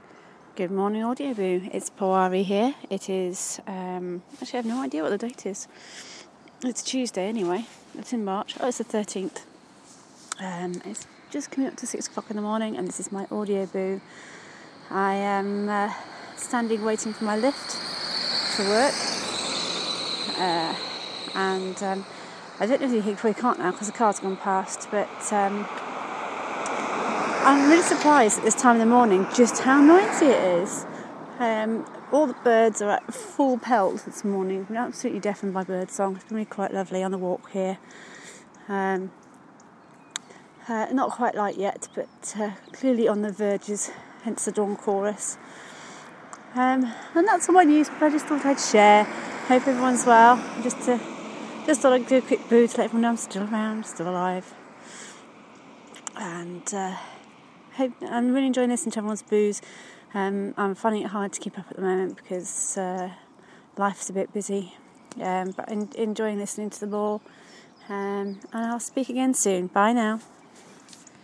Dawn Chorus